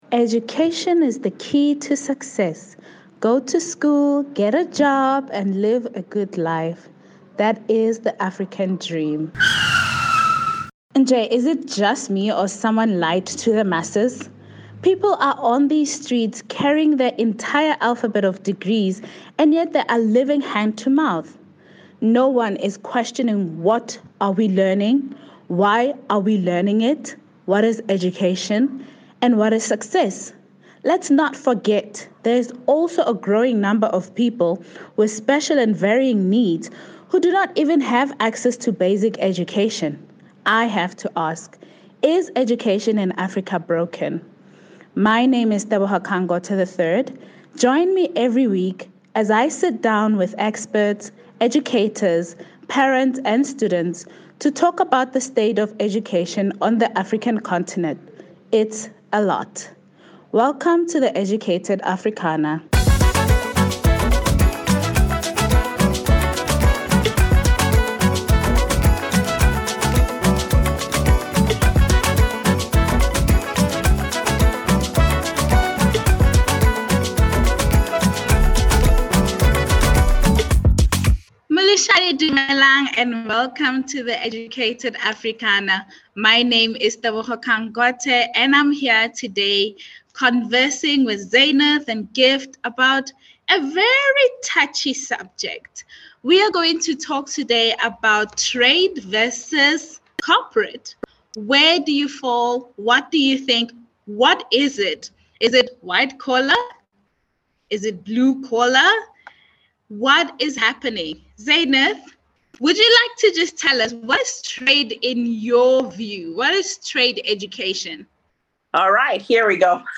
have an exciting discussion about the pros and cons of both trades vs. corporate career paths.